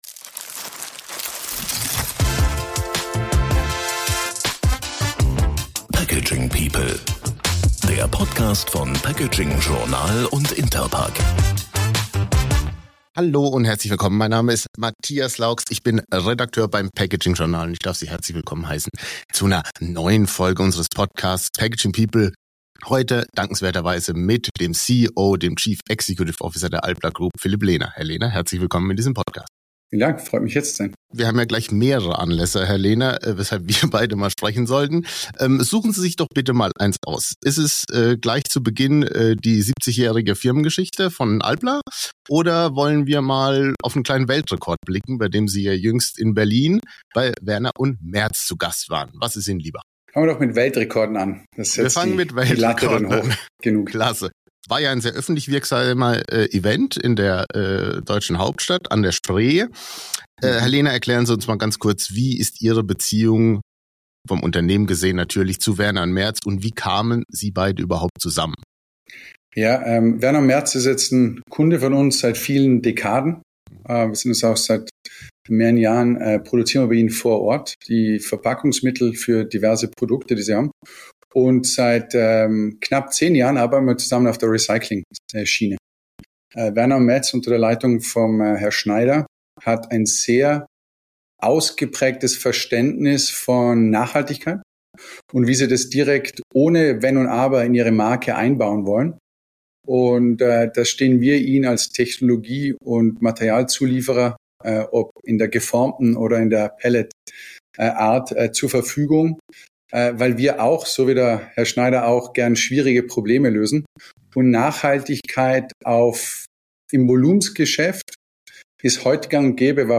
im Gespräch über Innovation, Verantwortung und die Zukunft der Kunststoffverpackung.